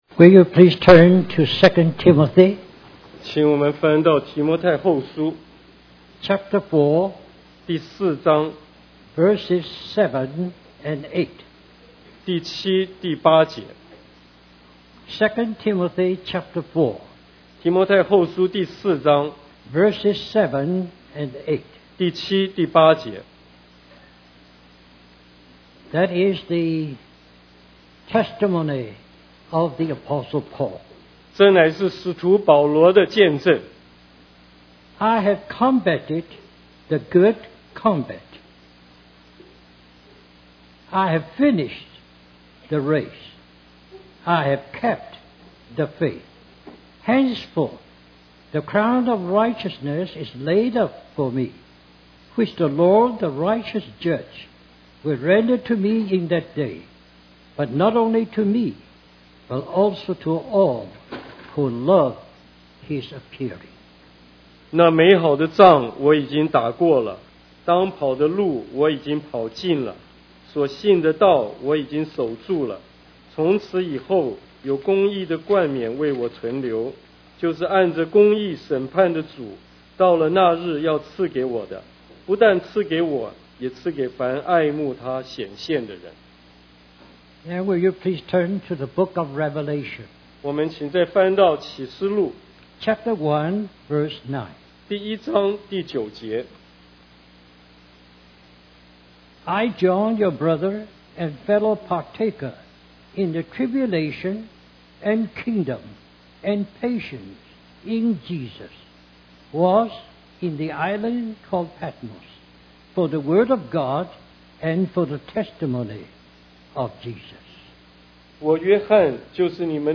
A collection of Christ focused messages published by the Christian Testimony Ministry in Richmond, VA.
West Coast Christian Conference